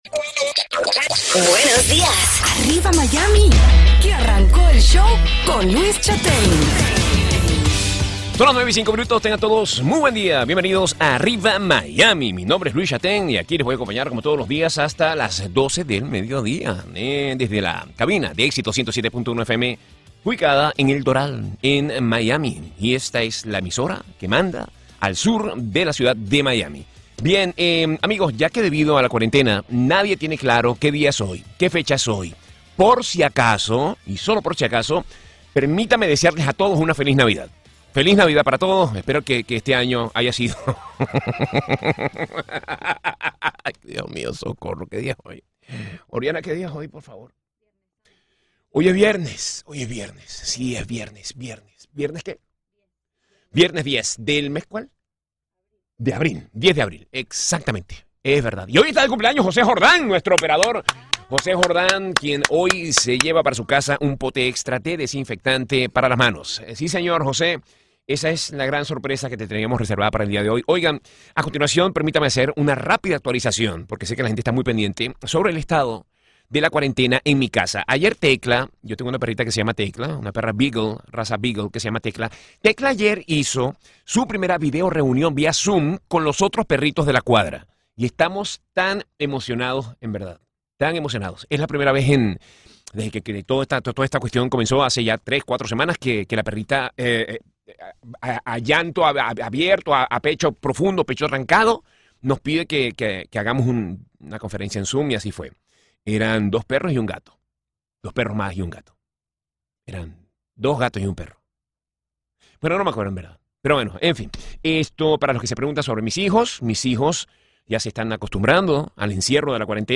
"Arriba Miami" transmitido por Exitos 107.1FM